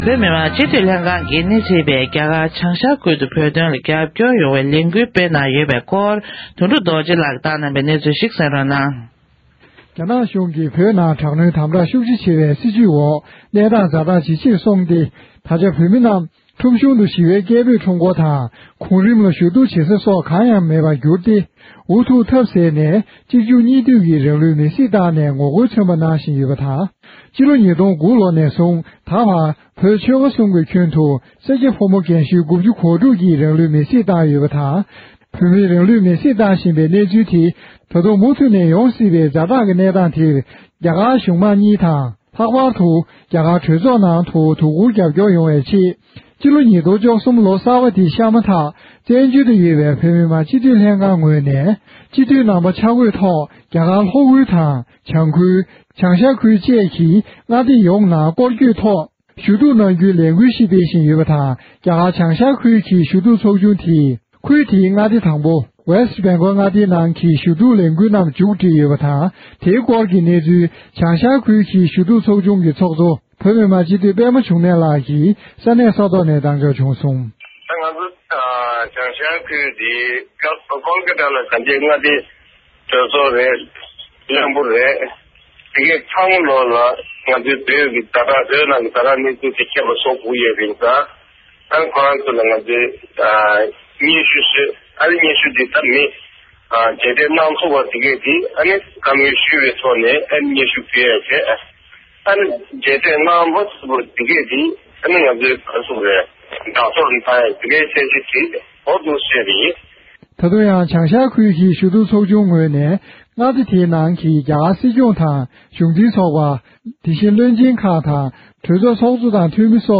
སྒྲ་ལྡན་གསར་འགྱུར། སྒྲ་ཕབ་ལེན།
སྤྱི་འཐུས་ཟླ་སྒྲོན་ཤར་གླིང་ལགས་ནས་འདི་ག་རླུང་འཕྲིན་ཁང་ལ་གནས་ཚུལ་གནང་དོན།